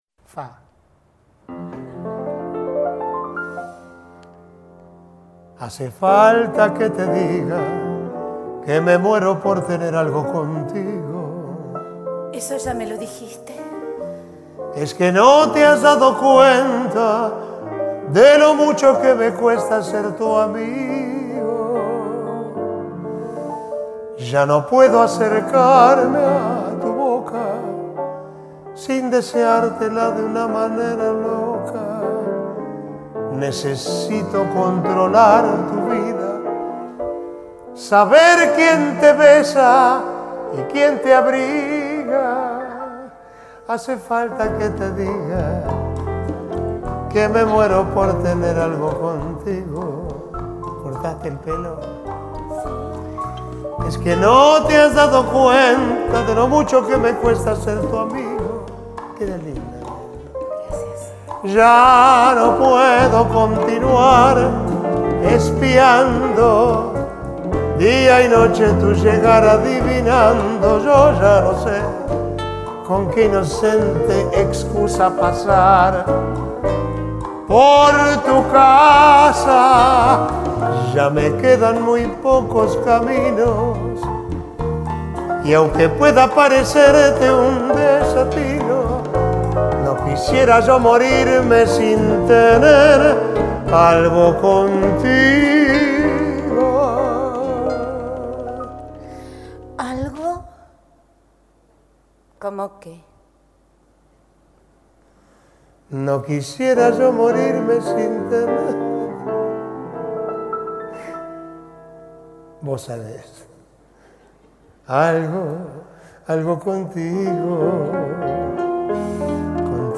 bajo